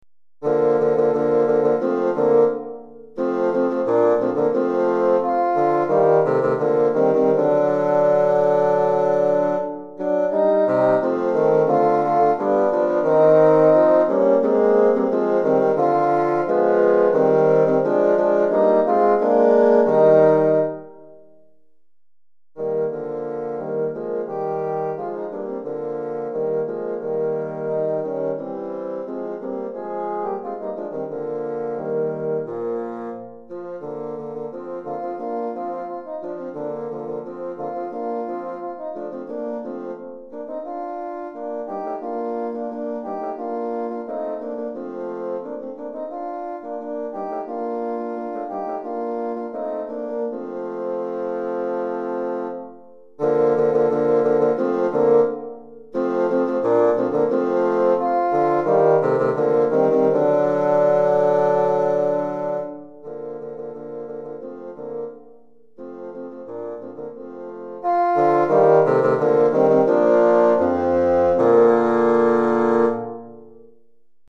3 Bassons